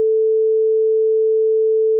WAV PCM 16-bit — 44100 Hz — mono — 2 secondes
Un fichier WAV a été intercepté sur le réseau. Il ne contient en apparence qu'une sinusoïde à 440 Hz — un simple son de référence.
La modification est inaudible — mais bien présente.